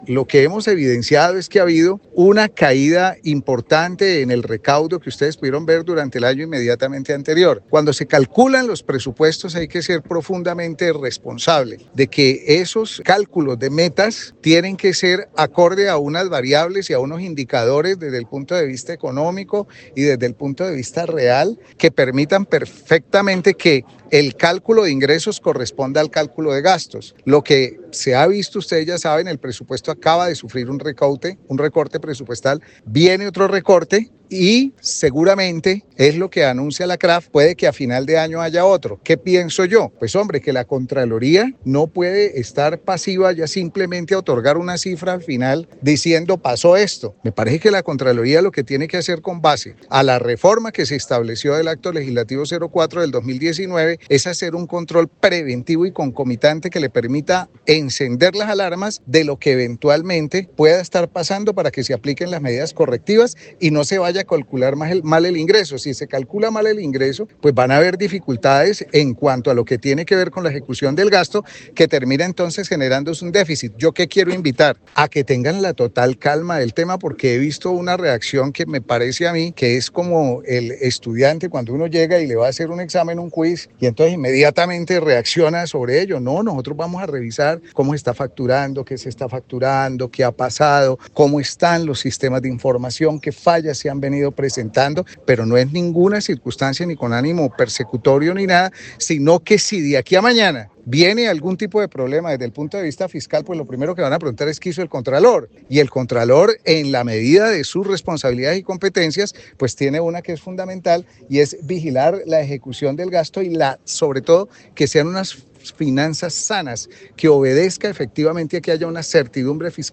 Desde Pereira el Contralor General de la República, se refirió a su decisión de ordenar seguimiento permanente a la gestión de fiscalización y recaudo tributario de la DIAN, expresó que esto permitirá emitir alertas tempranas sobre el recaudo tributario en el país.